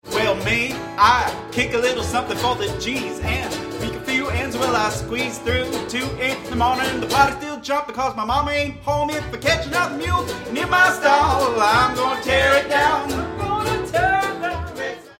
Here’s a few more experiments with the Line6 TonePort UX2 hardware / GearBox software, this time at the Paint Branch Ramblers practice for November 20, 2008.
This is equalized a bit using Audacity’s RCA Victor 1938 setting.
washtub bass
soprano ukulele
violin
guitar). When I’m not singing, I’m playing jug